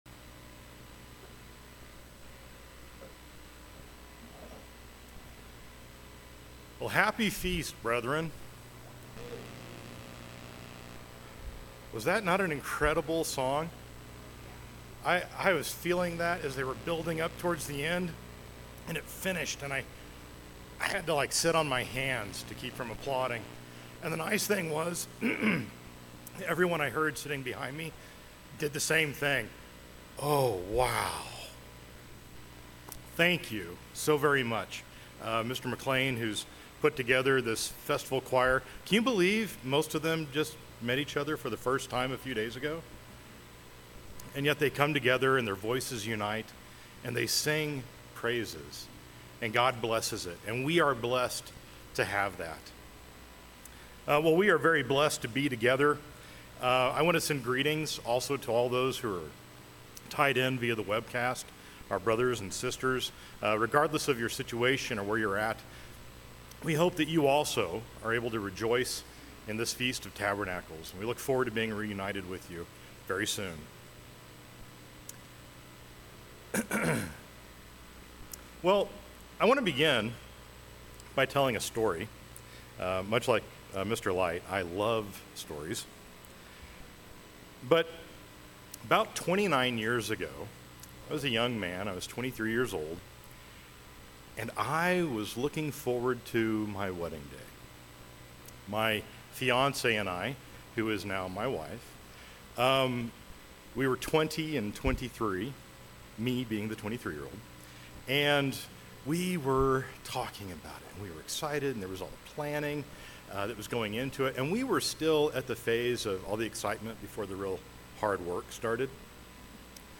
Split Sermon 2 - Day 4 - Feast of Tabernacles - Klamath Falls, Oregon
This sermon was given at the Klamath Falls, Oregon 2024 Feast site.